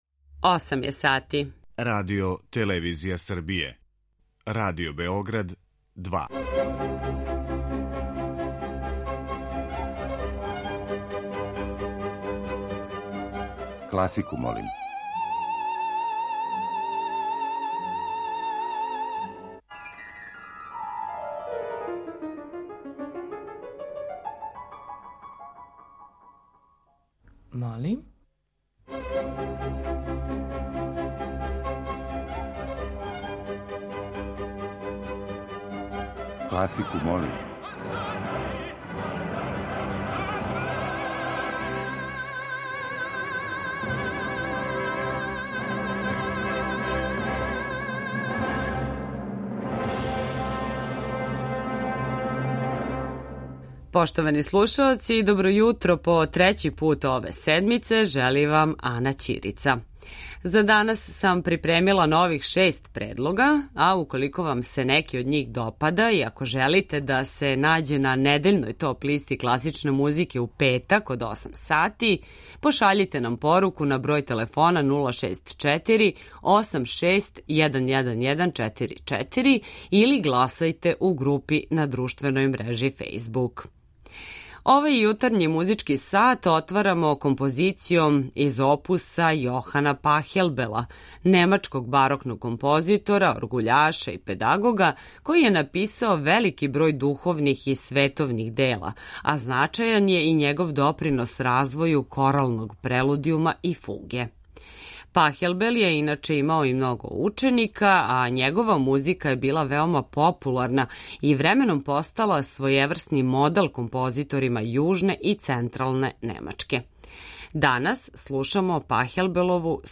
У години када оркестар наше куће прославља 85 година постојања, последњи јунски циклус емисија Класику, молим! биће обједињен овогодишњим снимцима са концерата Симфонијског оркестра РТС.